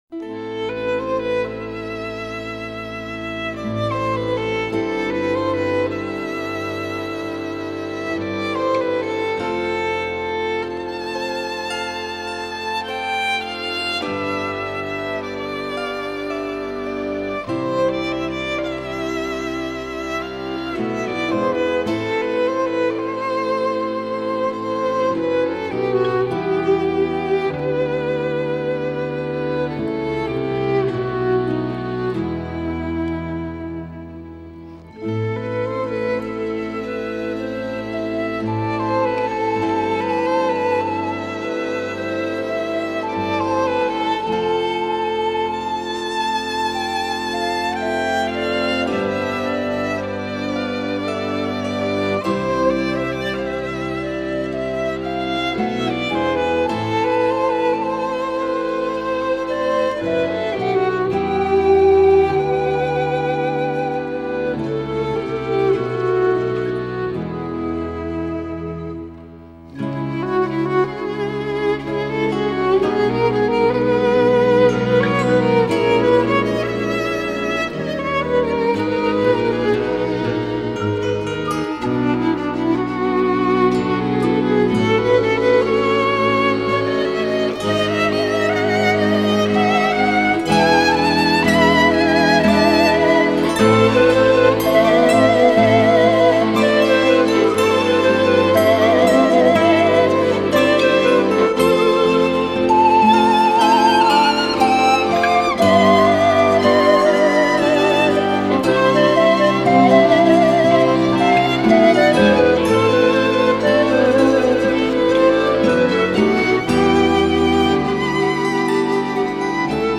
скрипка, бандура, сопілка, цимбали